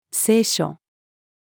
清書-female.mp3